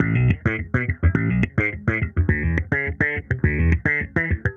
Index of /musicradar/sampled-funk-soul-samples/105bpm/Bass
SSF_JBassProc1_105B.wav